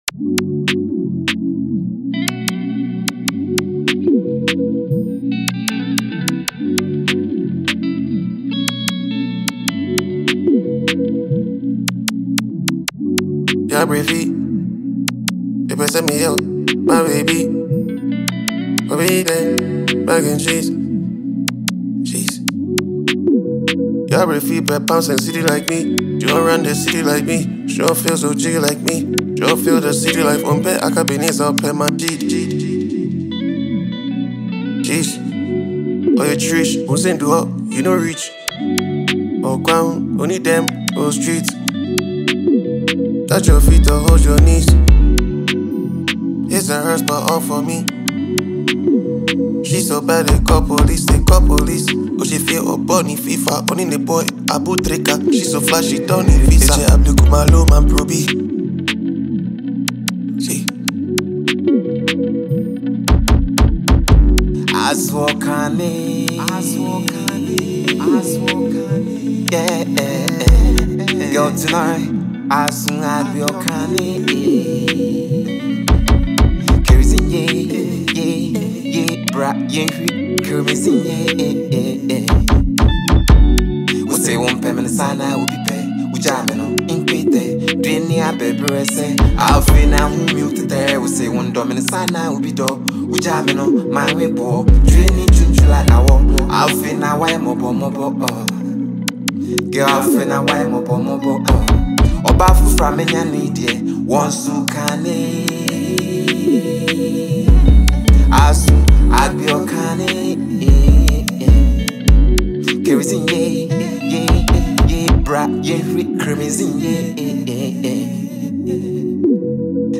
Ghanaian singer and songwriter
Comic rapper